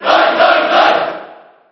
File:Roy Koopa Cheer JP SSB4.ogg